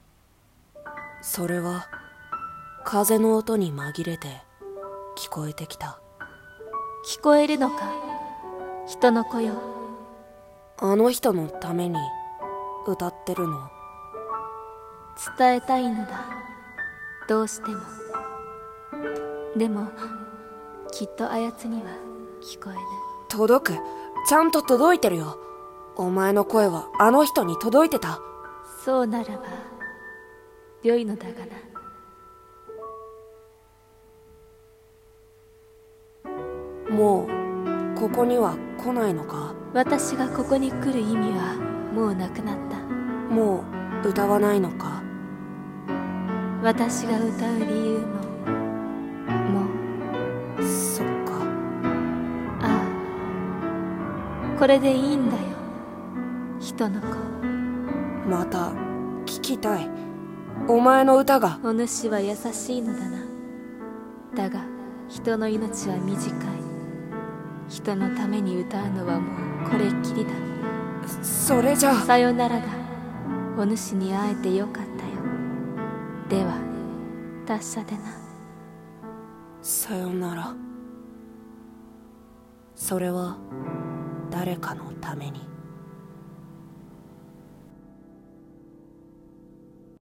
【声劇 台本】それは、誰かのために【掛け合い】